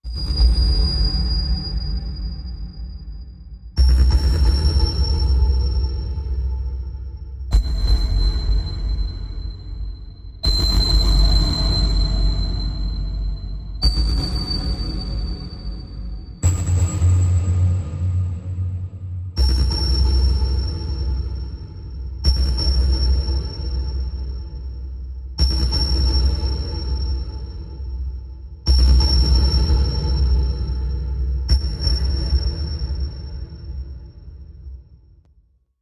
Scar Pulse, Machine, Reverb Glassy Hits, Deep Echoes